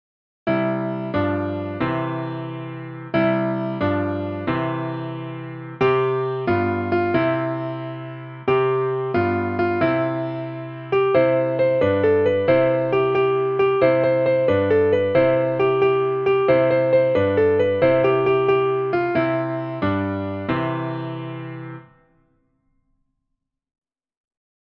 Nursery Rhymes:
for piano